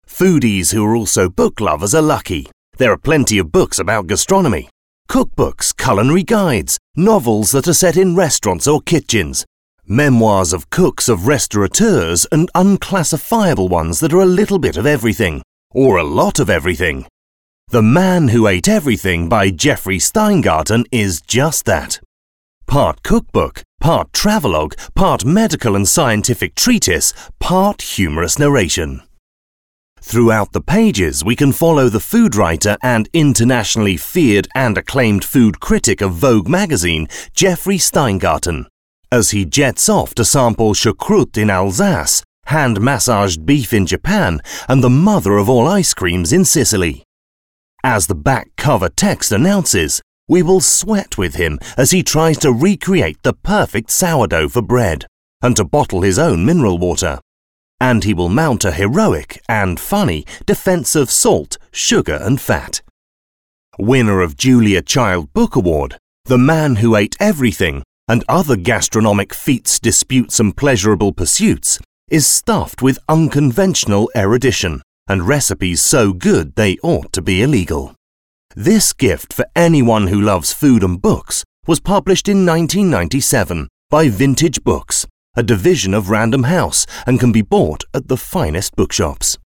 Extra Listening 2: Book Review